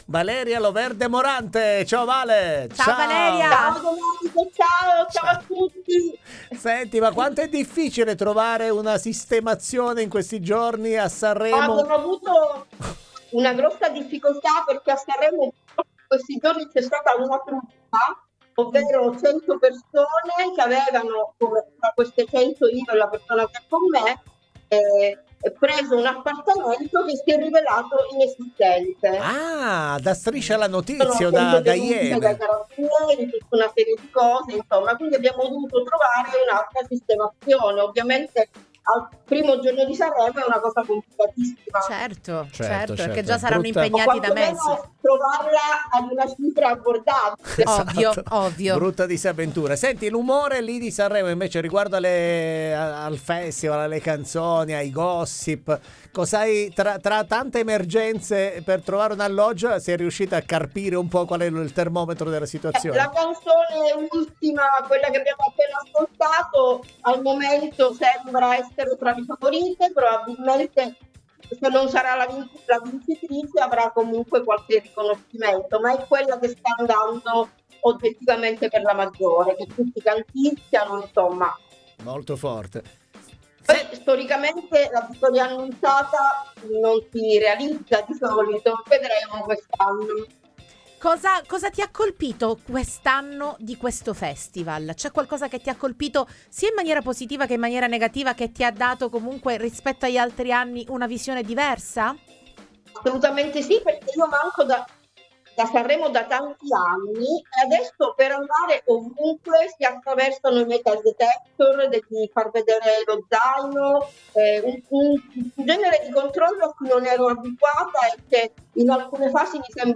IN COLLEGAMENTO DA SANREMO